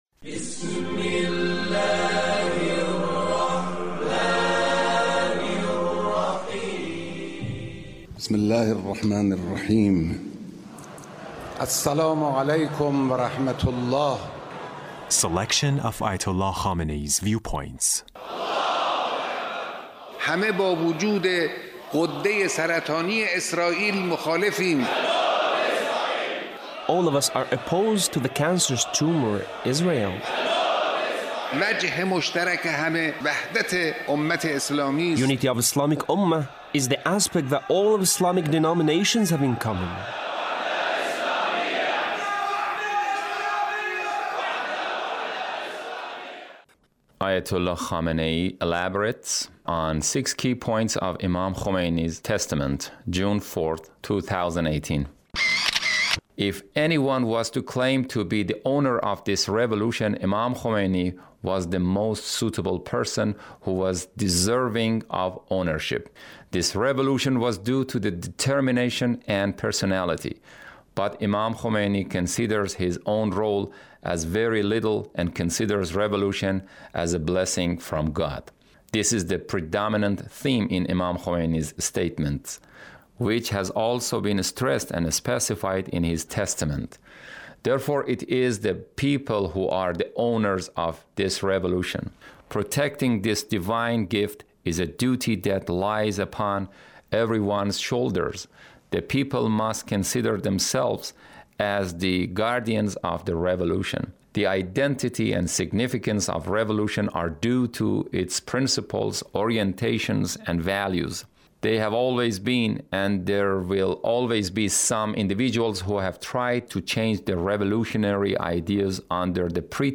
Leader's Speech about the Imam Khomeini